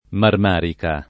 [ marm # rika ]